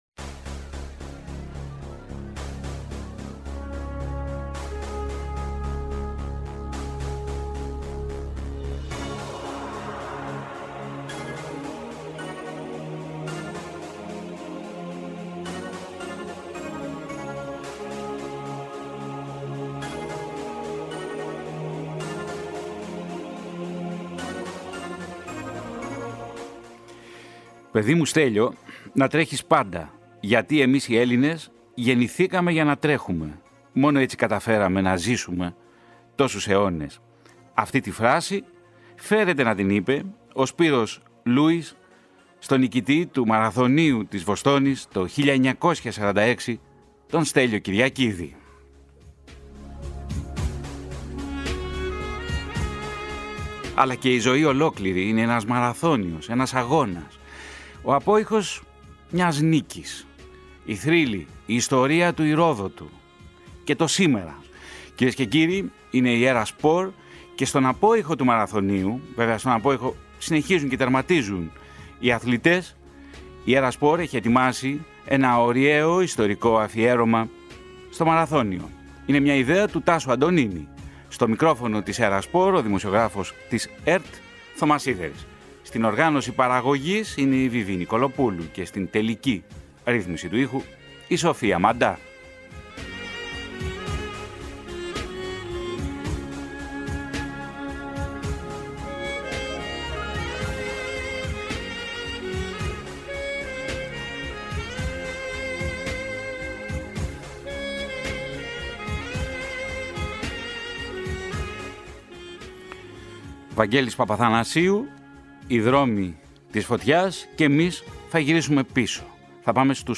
Παράλληλα, ακούγονται σπάνια ηχητικά τεκμήρια από το αρχείο της ΕΡΤ από τα έτη 1955 και 1967.